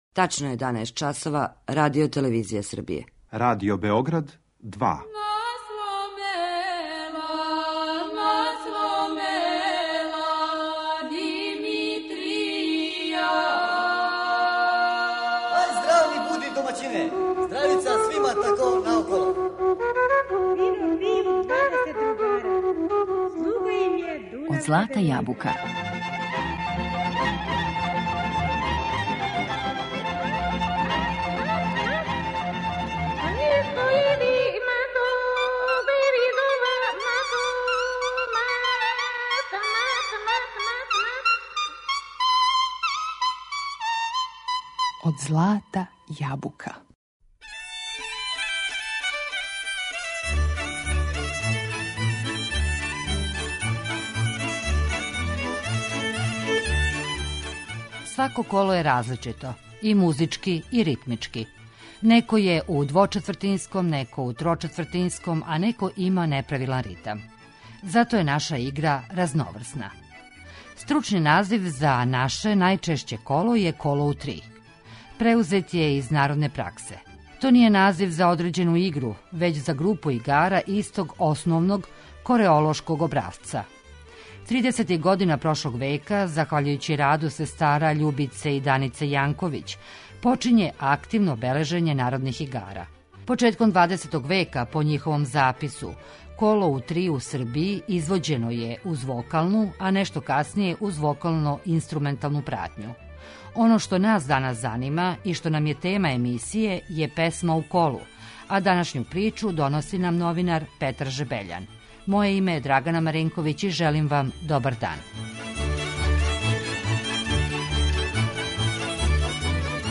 У данашњој емисији издвојићемо неке народне песме које су певане у колу.